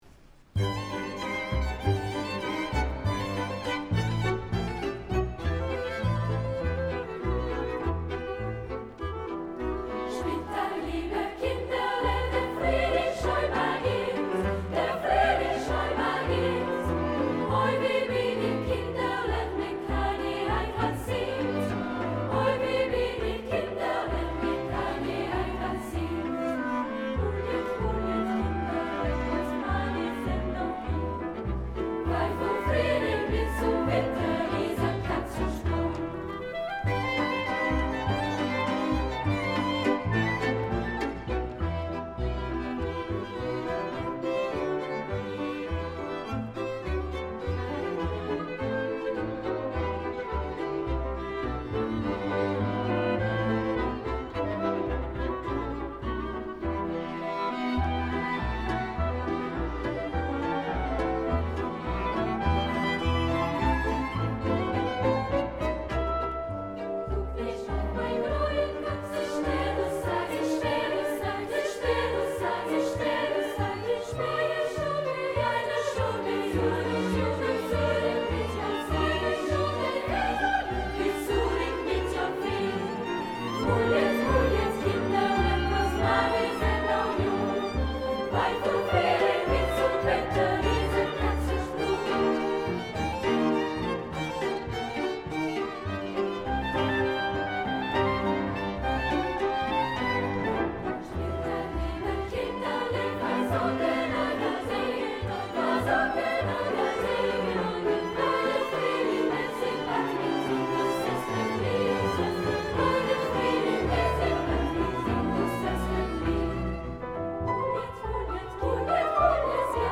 ARMENIAN, KLEZMER AND GYPSY MUSIC